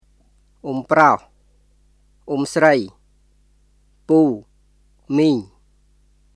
[オム（プロホ／スライ）、プー、ミーン　ʔom (proh / srəi),　puː,　miːŋ]